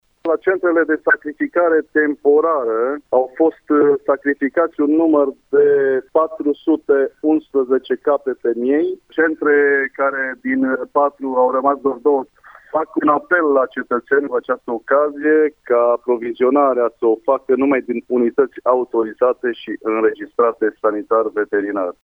Șeful Direcției Sanitar Veterinare și pentru Siguranța Alimentelor Mureș, Vasile Oprea: